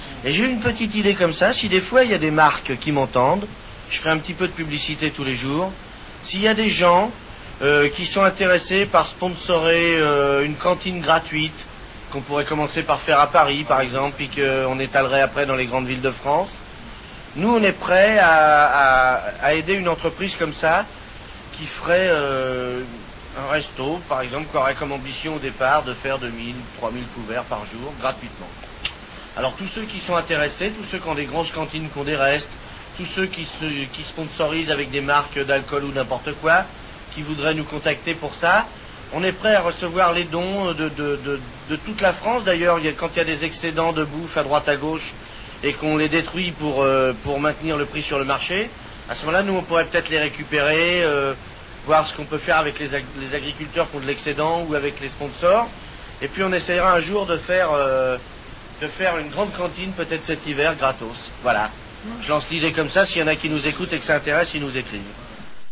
Europe1-Coluche-cree-resto-du-coeur.mp3